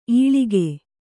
♪ īḷige